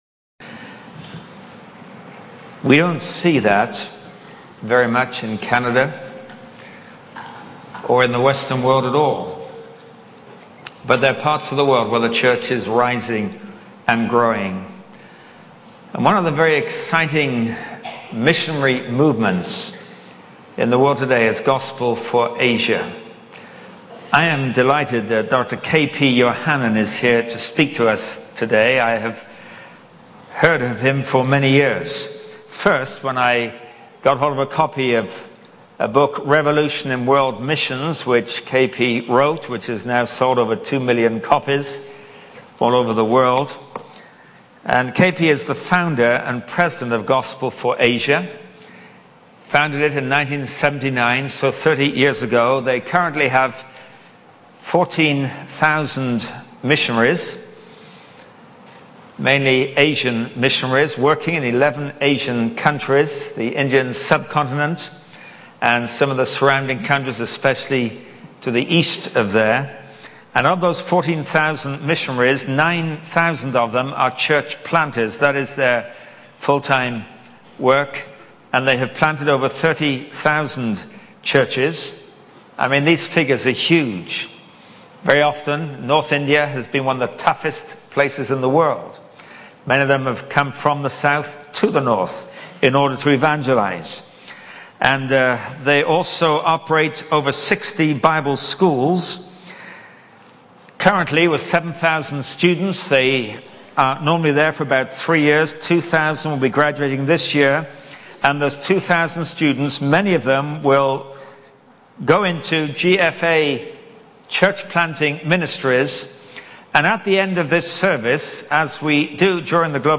In this sermon, the speaker shares a story of a missionary who faced great difficulties and suffering while working among a people group in India.